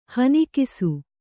♪ hanikisu